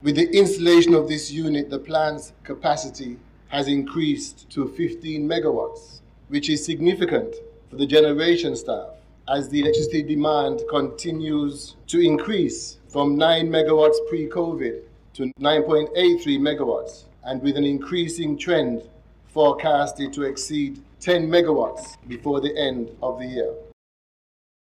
The Commissioning Ceremony of a new 3.8 Megawatt Wӓrtsilӓ Generator was held on Tuesday, March 15th, at the NEVLEC (Nevis Electricity Company Limited) Power Station, in Prospect, Nevis.